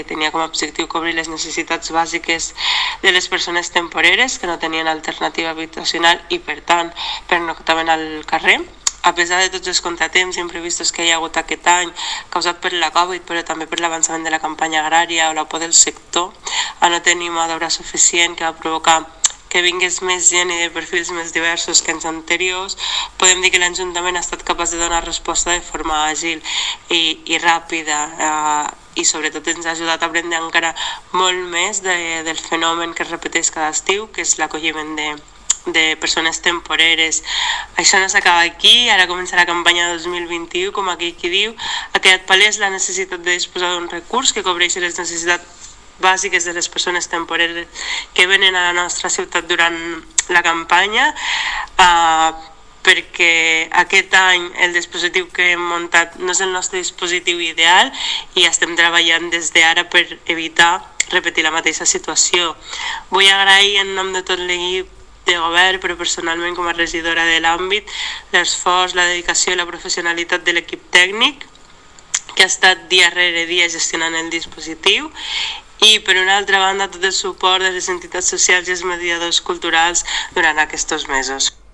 tall-de-veu-de-la-regidora-mariama-sall